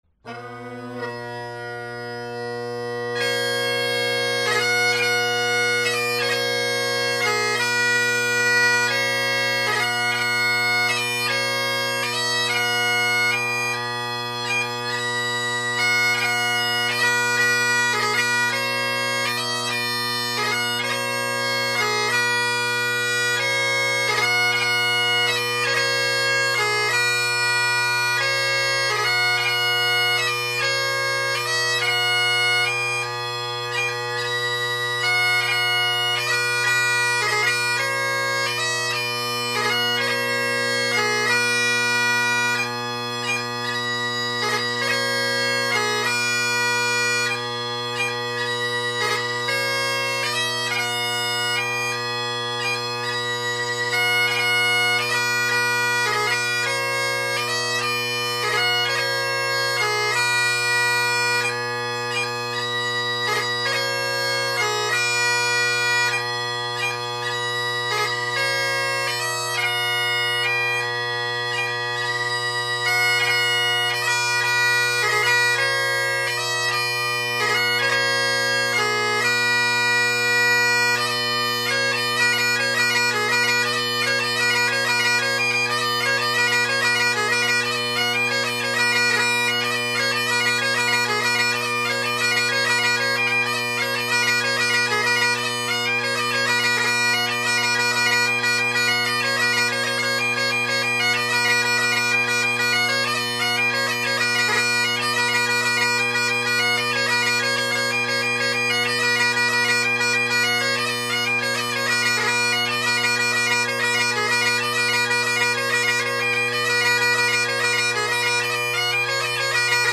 Drone Sounds of the GHB, Great Highland Bagpipe Solo
The recordings below were artificially amplified as I forgot to reset the gain on my Zoom H2 after setting it to a quieter setting yesterday.
You might hear a cell phone ring at some point.
Still a little tenor quiet, but I believe this lends just a little more ease when tuning the drones along with some added stability.